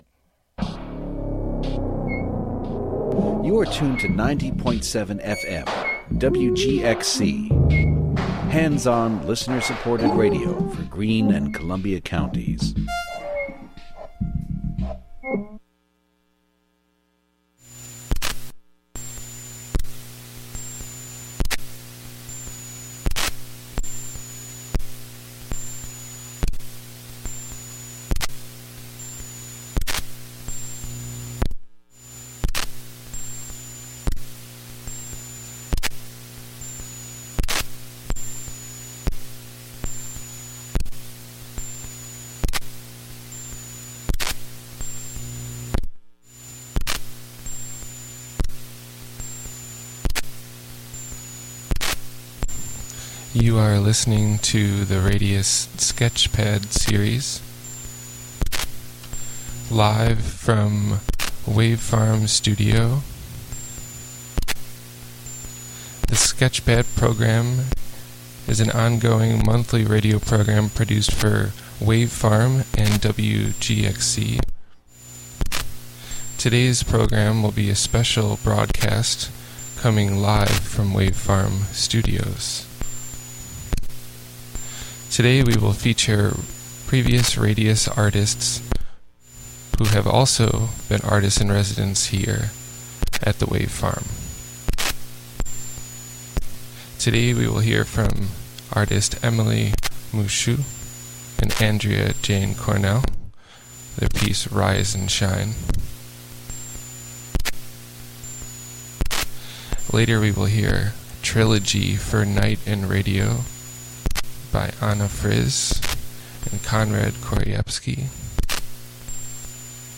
Radius provides artists with live and experimental formats in radio programming.